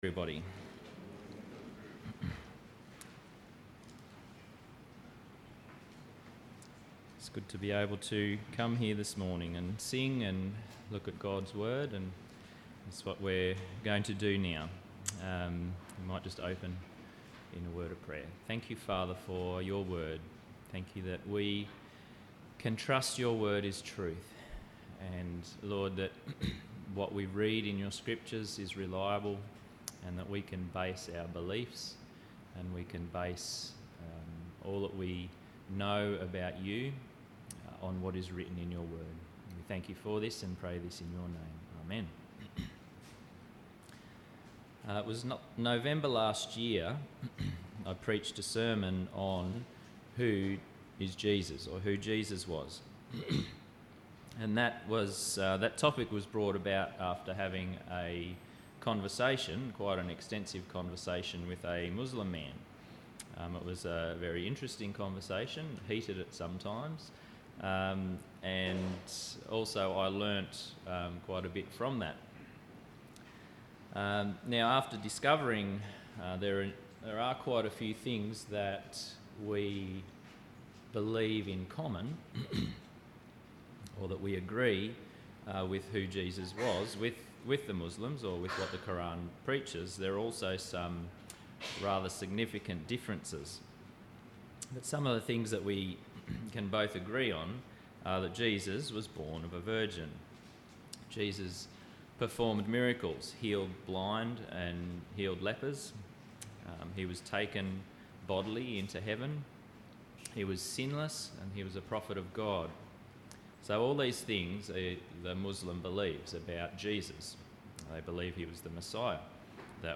Sunday Service Audio from 18/02/18